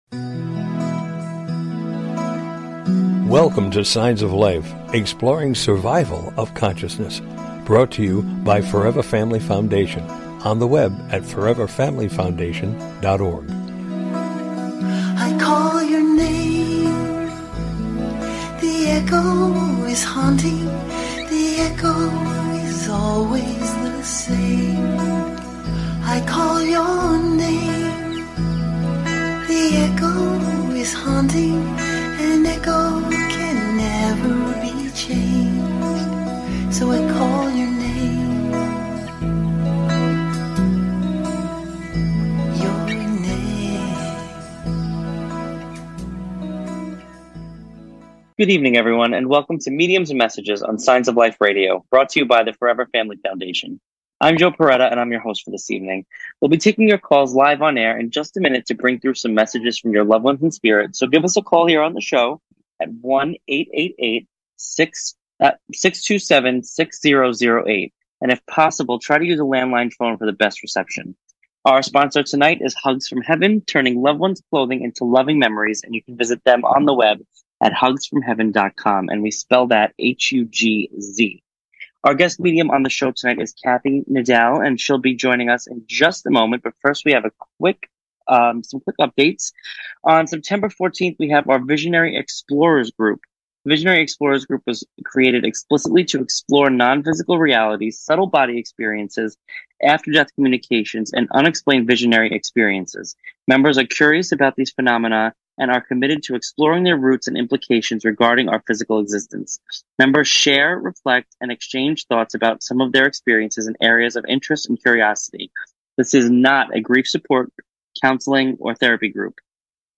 Talk Show Episode
This Show Format invites listeners to call in for "mini readings."